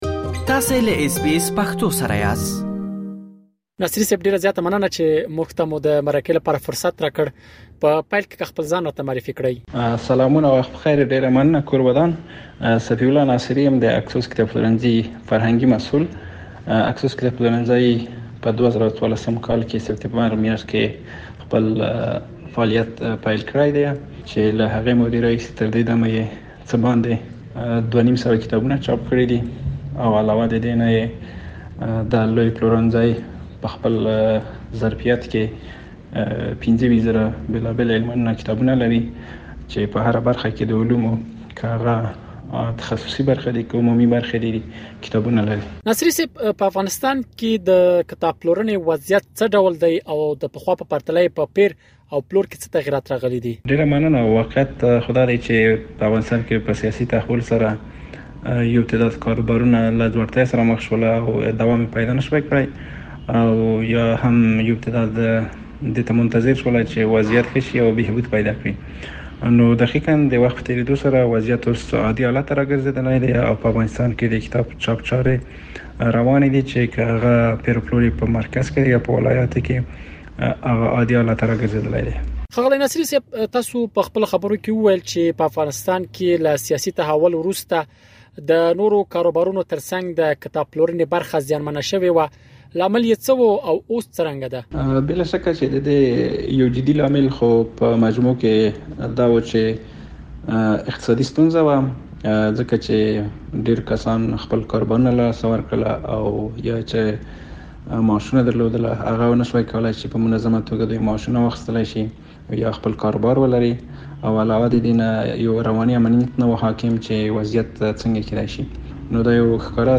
په افغانستان کې د کتاب پلورنې وضعیت څه ډول دی او د پخوا په پرتله يې په پېر او پلور کې څه تغیرات راغلي دي؟ اس بي اس پښتو په کابل کې د کتابونو د پېر او پلور په اړه له یوه کتاب پلورونکي سره مرکه ترسره کړې.